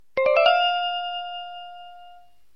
Звуки правильного и неправильного ответа